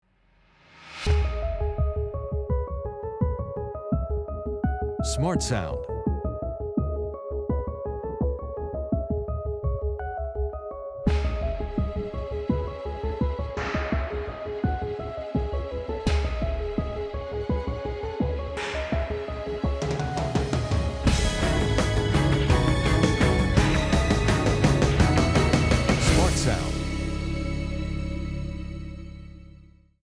Will you listen to several short pieces of background music that we are considering using in a 30 second trailer/advert for the pilot programme?